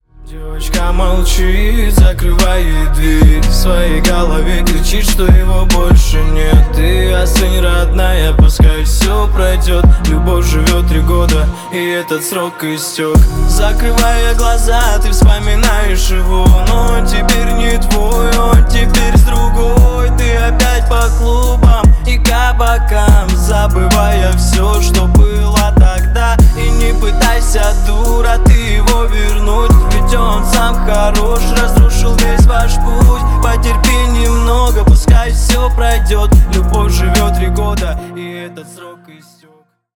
• Качество: 320 kbps, Stereo
Рэп и Хип Хоп
спокойные
грустные